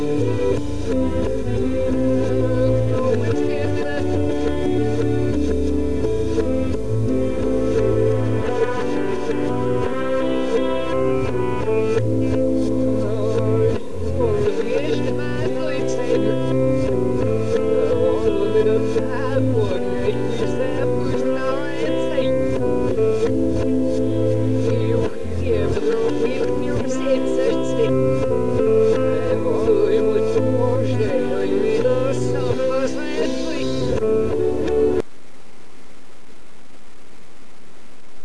These messages begin at about second 12 of the .wav above.